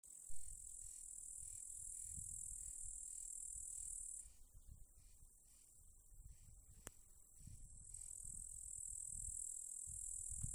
Corn Crake, Crex crex
StatusSinging male in breeding season
Notes/patreiz tur ir āboliņa lauks, blakus labības lauki